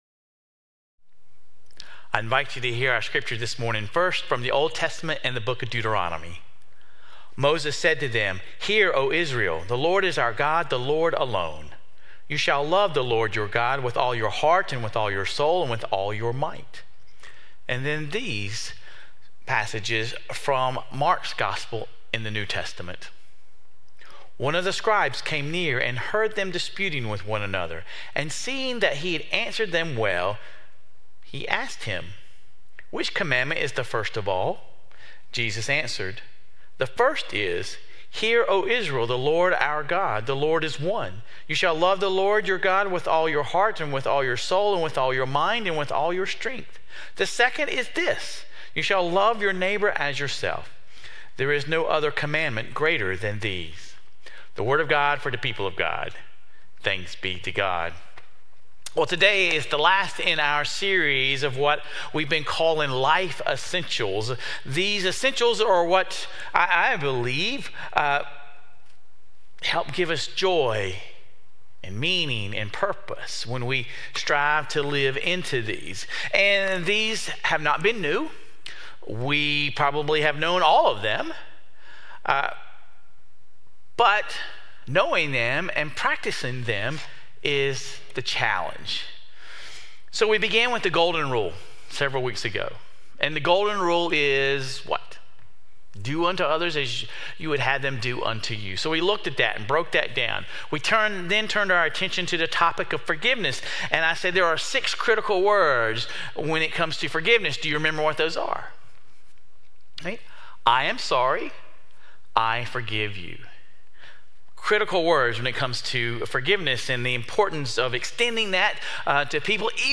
This week, we explore the ultimate life essential: Loving God and Loving Others. Sermon Reflections: Why do you believe in God?